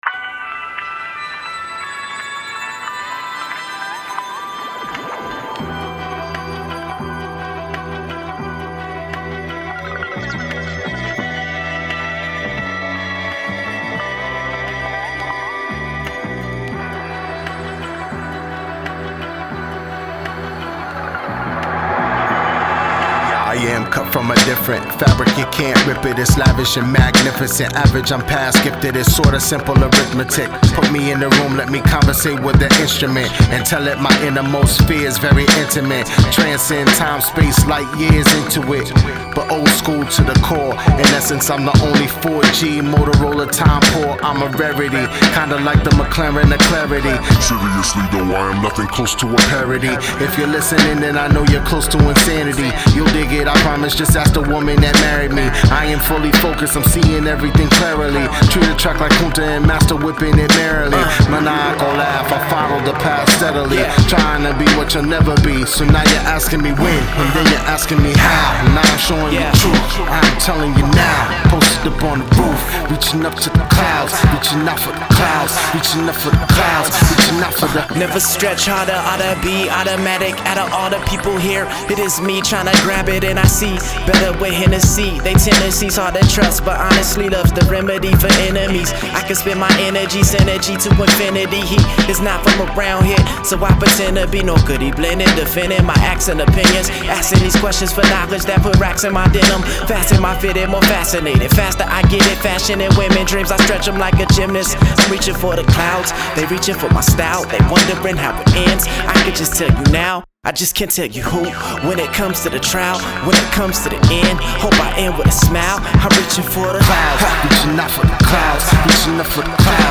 melodic, well-made Hip-Hop